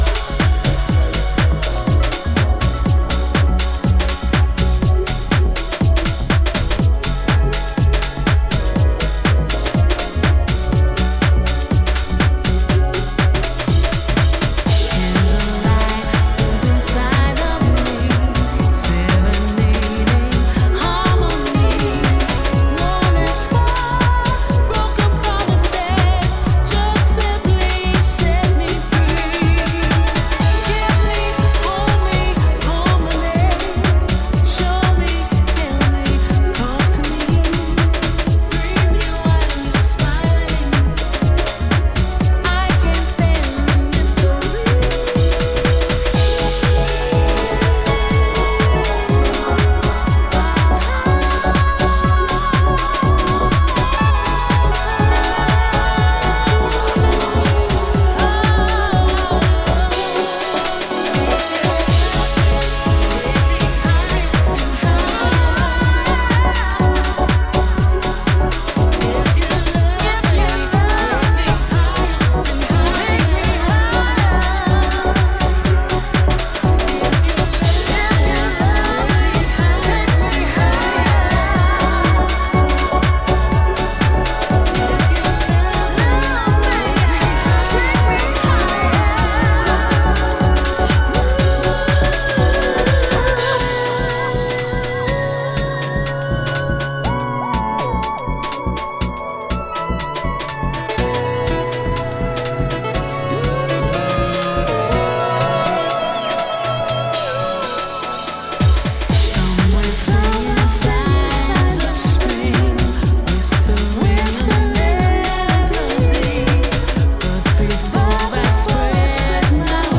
温かく洗練されたヴォーカル・ハウスに仕立てています。